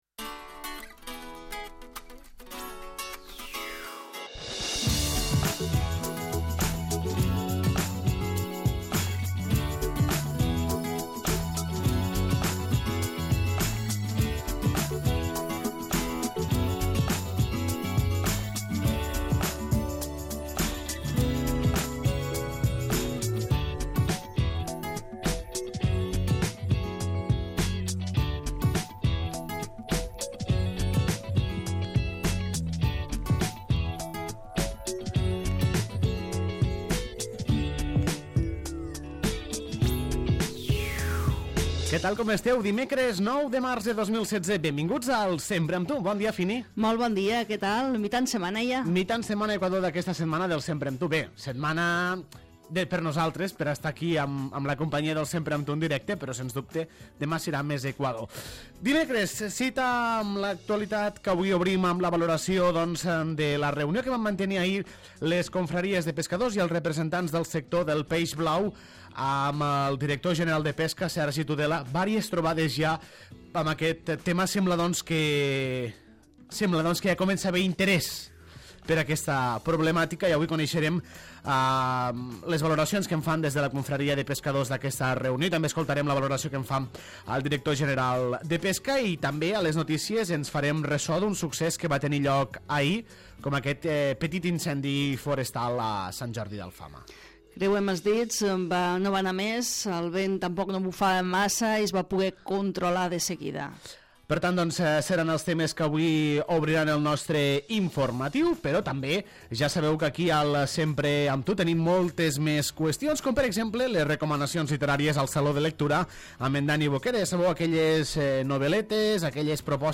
Benvinguts al 39è programa de la nova temporada del Sempre amb tu, el magazín dels migdies de La Cala Ràdio que correspon a l'edició de dimecres 9 de març de 2016.
A la segona part ens acompanya a l'Entrevista el regidor de Joventut, Vicenç Llaó, amb qui coneixem les activitats que es desenvoluparan al Parc de Setmana Santa i sabem l'estat de les inscripcions.